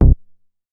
MoogMini 010.WAV